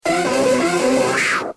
Cri d'Hypnomade dans Pokémon X et Y.